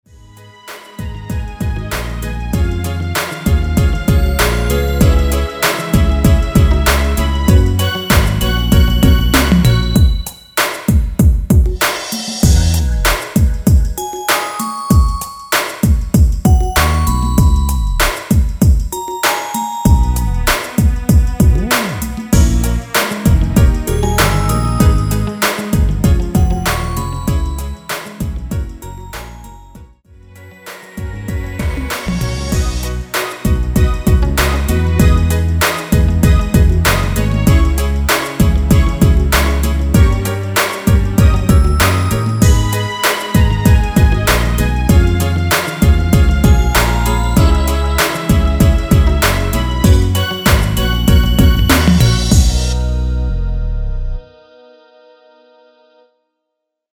중간 간주 랩 없이 진행되고 마지막 랩 없이 끝납니다.(본문 가사 확인)
원키에서(-1)내린 랩부분 삭제 편곡한 MR 입니다.(미리듣기 참조)
앞부분30초, 뒷부분30초씩 편집해서 올려 드리고 있습니다.
중간에 음이 끈어지고 다시 나오는 이유는
곡명 옆 (-1)은 반음 내림, (+1)은 반음 올림 입니다.